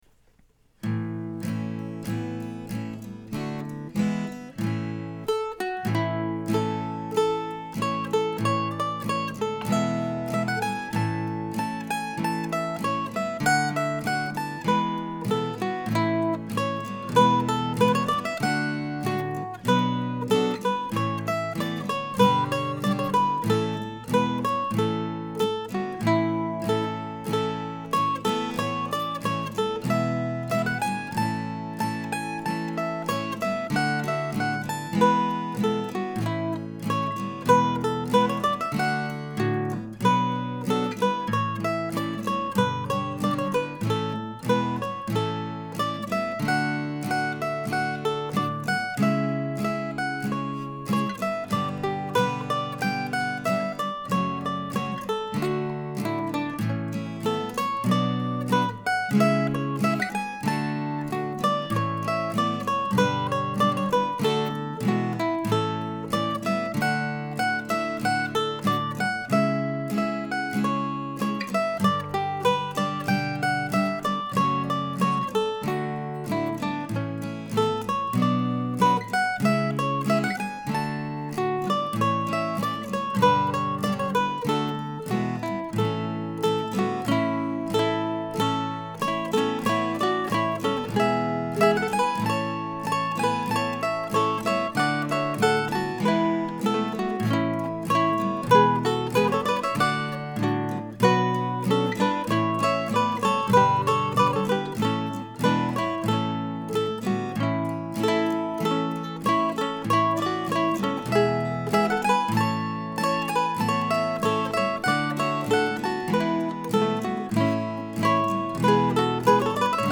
This week's tune is an Andante, a walking tune in the key of A major.
Regarding the tune itself, I was pleased that once again I was able to make use of my old Flatiron octave mandolin near then end.